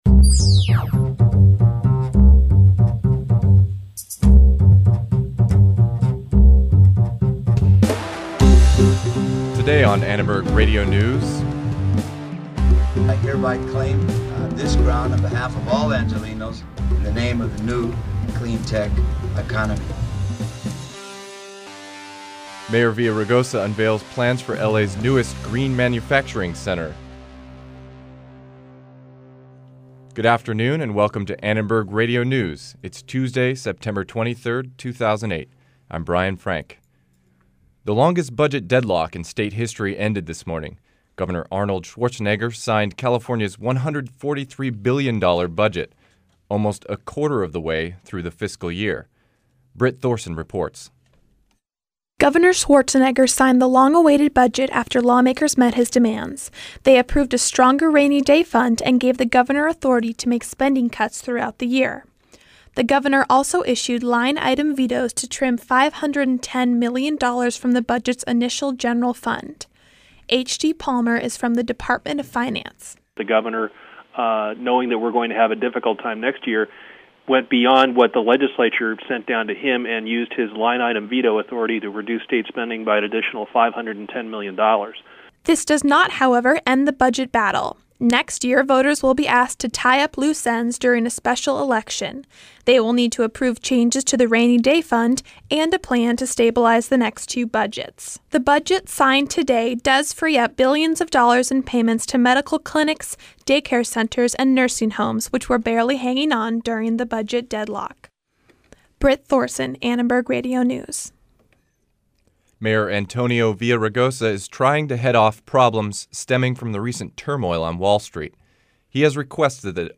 ARN Live Show - September 23, 2008 | USC Annenberg Radio News
We caught up with Mayor Villaraigosa as he unveiled the site of a proposed green manufacturing center that he says will help reduce emissions. Also, a local union holds a protest in downtown to fight for the rights of local student workers.